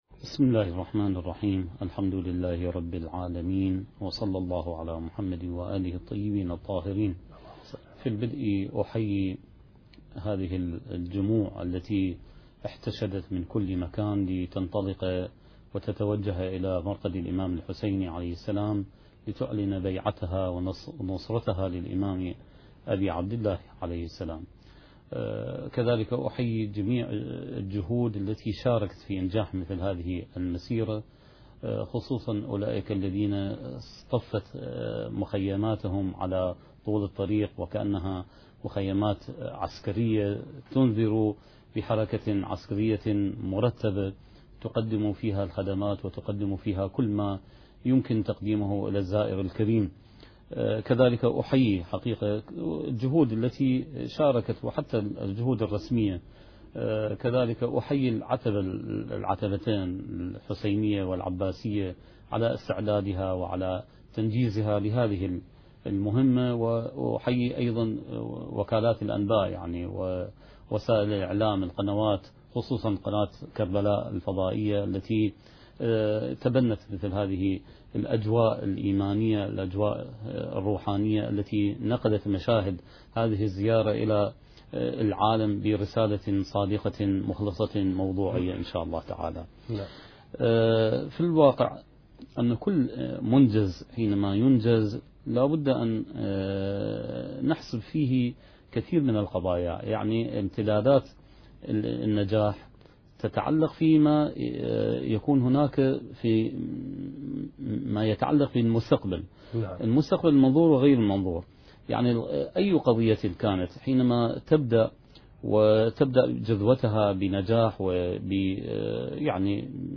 المكان: قناة كربلاء الفضائية